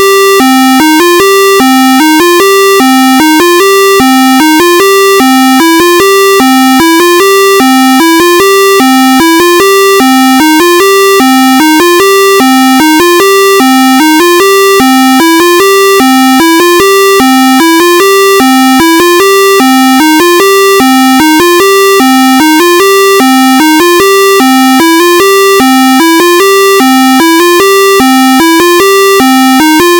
Sample Rate: 44100 Hz
Channels: 1 (mono)